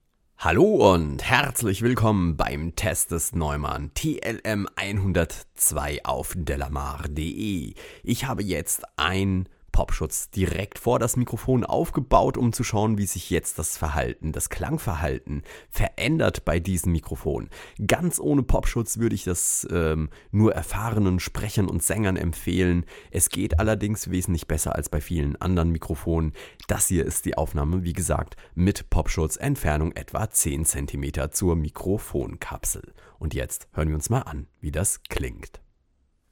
Und so verwundert es kaum, dass das Rauschen in einer normalen Tonstudioumgebung nicht zu vernehmen ist.
Generell klingt das Neumann TLM 102 relativ neutral und modern.